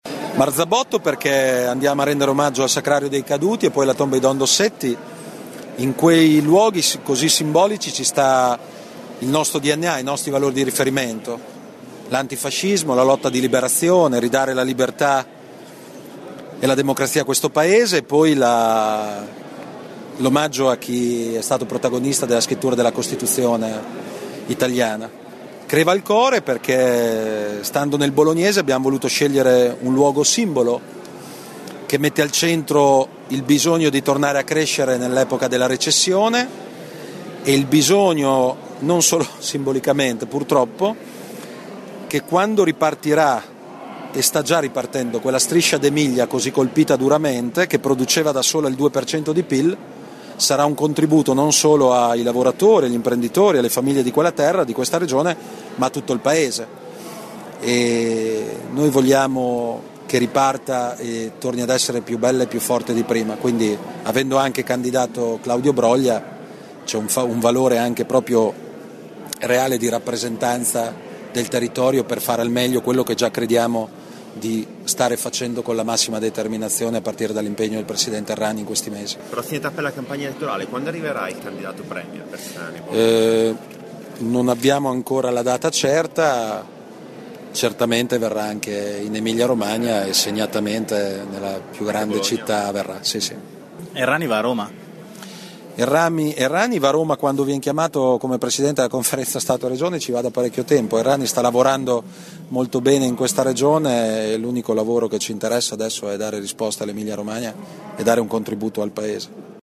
Questi gli ingredienti della presentazione di questa mattina dei candidati nelle liste emiliano romagnole del Partito Democratico alle prossime elezioni politiche.